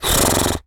horse_breath_03.wav